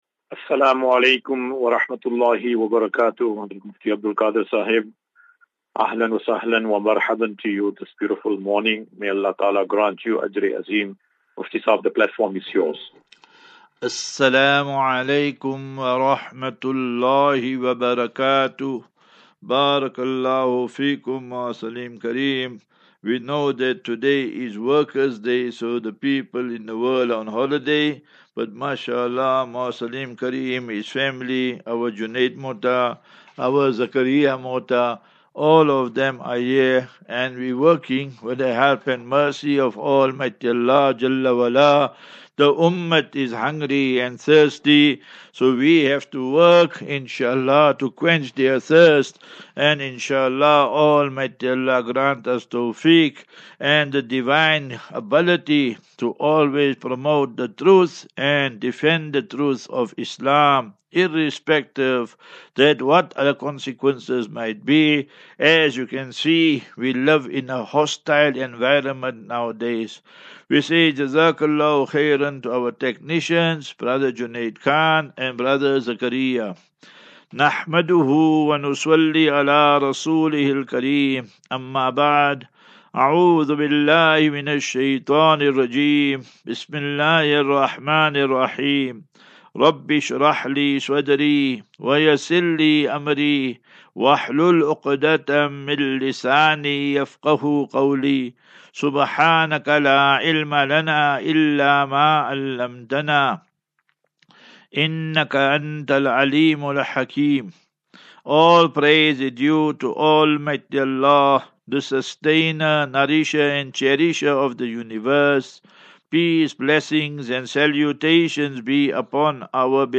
Assafinatu - Illal - Jannah. QnA
Daily Naseeha.